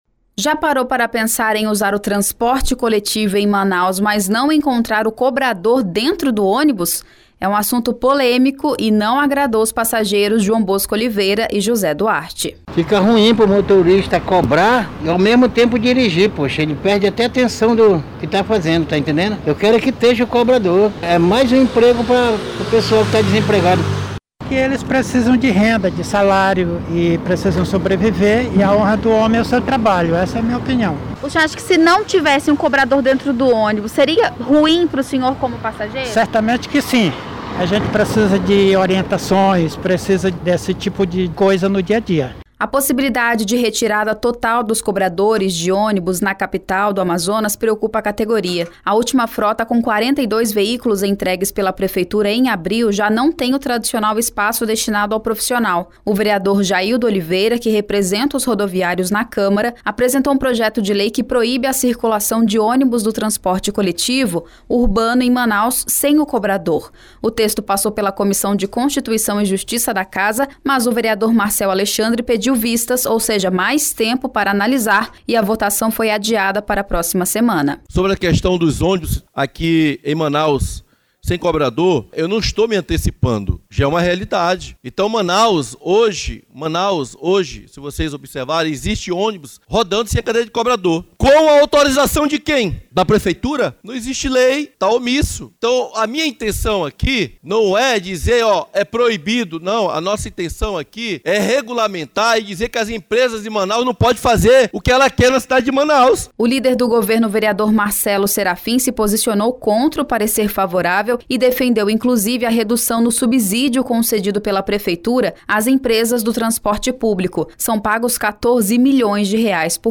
Entenda na reportagem: